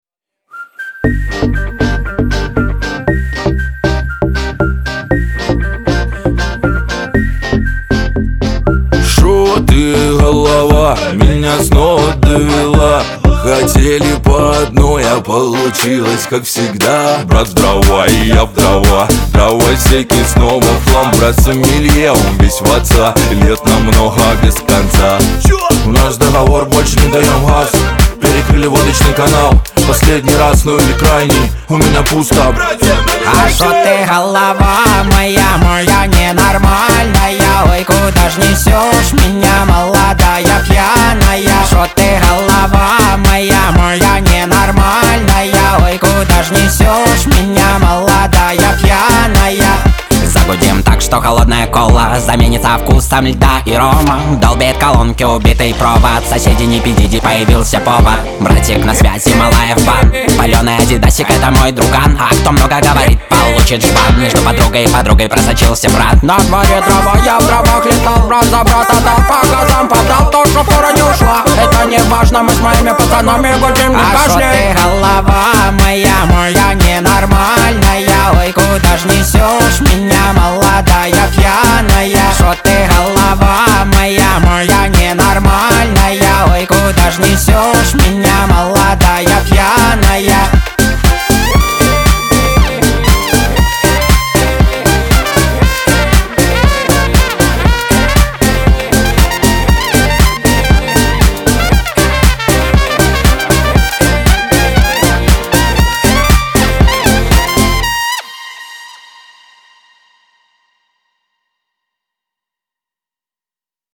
весёлая музыка
ансамбль